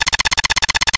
Sons et bruitages de jeux vidéos